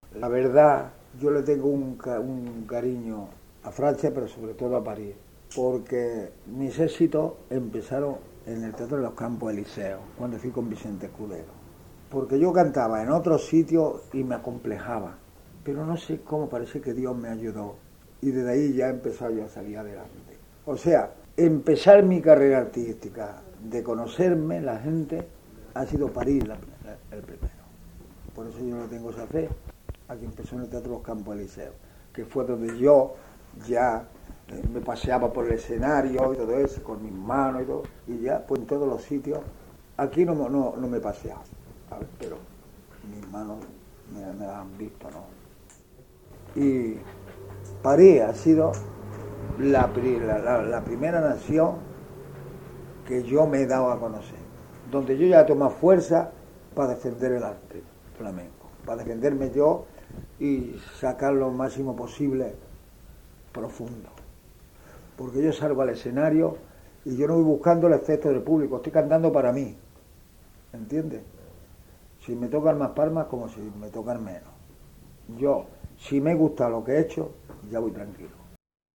Entrevista a Rafael Romero
NB : aussi souvent que possible, nous avons coupé les questions au montage. / lo más a menudo posible, hemos suprimido las preguntas.